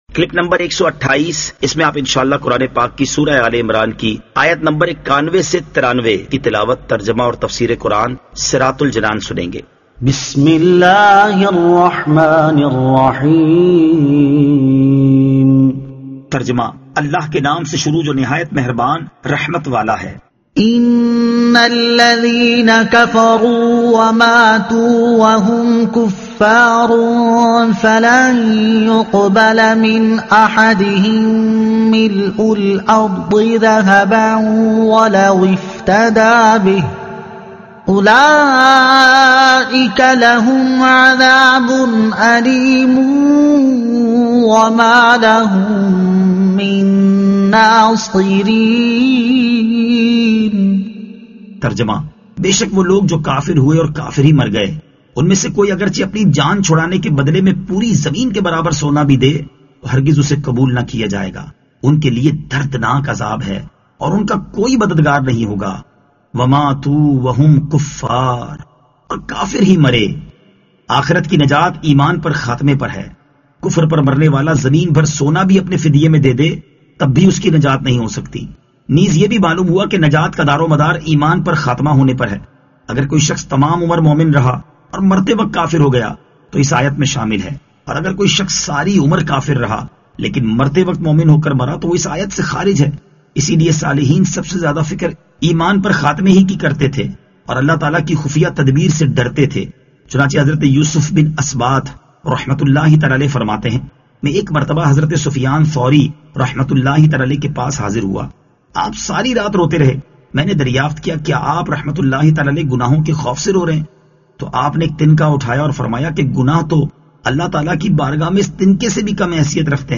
Surah Aal-e-Imran Ayat 91 To 93 Tilawat , Tarjuma , Tafseer